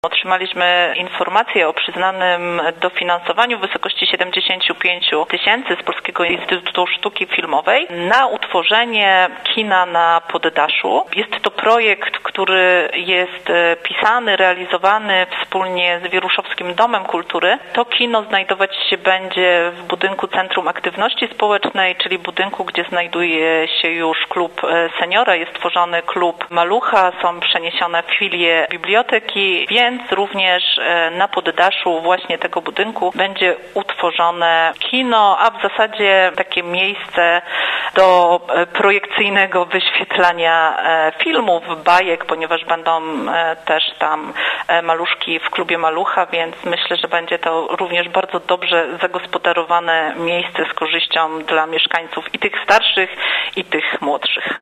– mówiła wiceburmistrz Wieruszowa, Marta Siubijak.